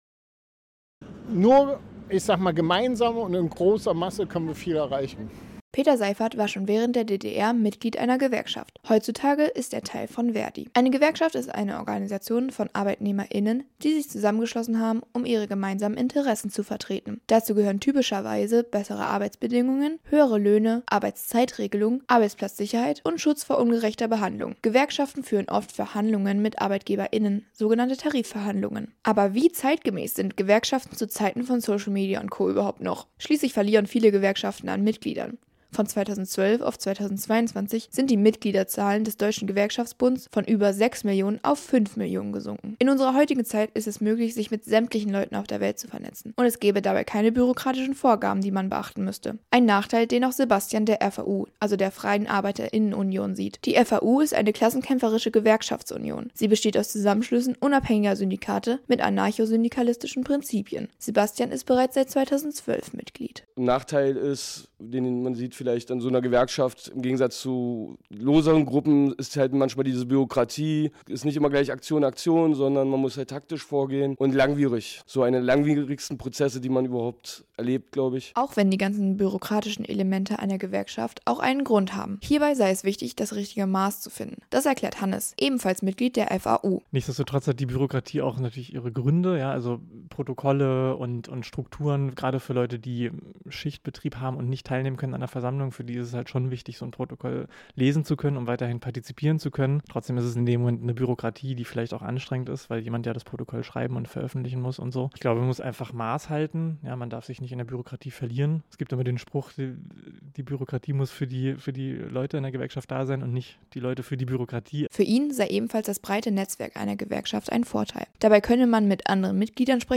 Was Gewerkschaften genau sind und welche Vor- oder Nachteile diese einem wirklich bieten, soll in dem Beitrag erklärt werden. Dafür wurde mit Mitgliedern der FAU und Verdi gesprochen. Es soll außerdem die Frage geklärt werden, wie zeitgemäß Gewerkschaften zu Zeiten von sozialen Medien und Co. noch sind.